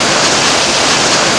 IDG-A32X/Sounds/SASA/CFM56/external/cfm-windrush.wav at 41640b0aab405391c8a4d8788da387d27aeb3097